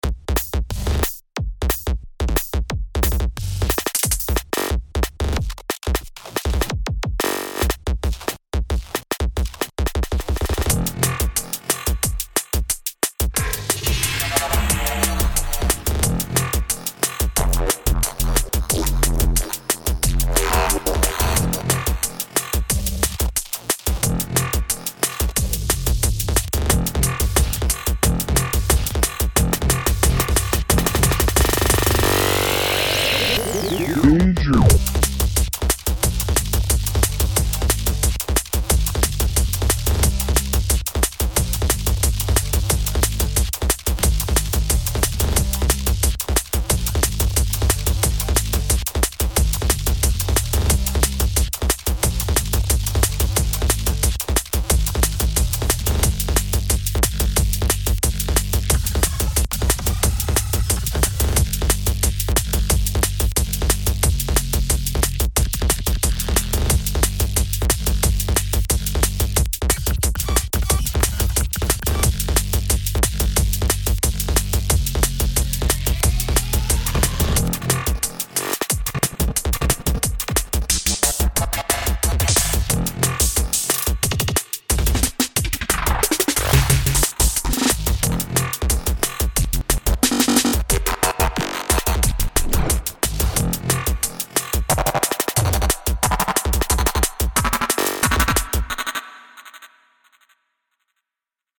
still working on some needlessly aggressive footwork flavored breakcore.